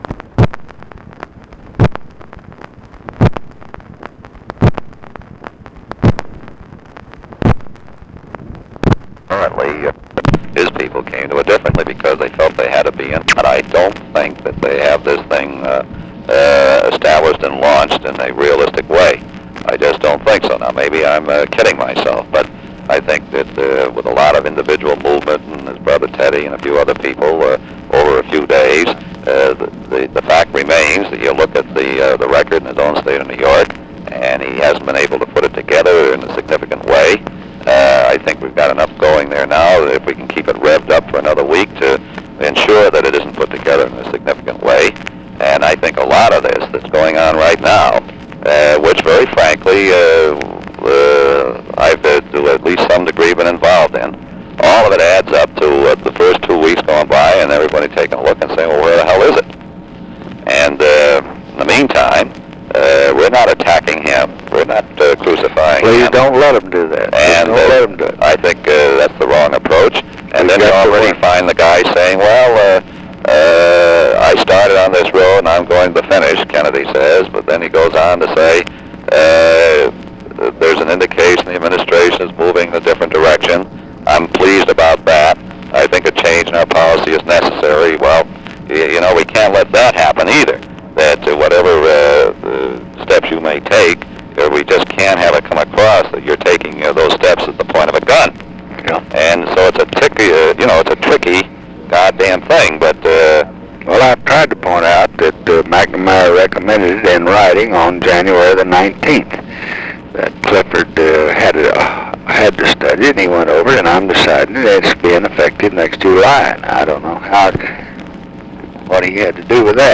Conversation with LARRY O'BRIEN, March 23, 1968
Secret White House Tapes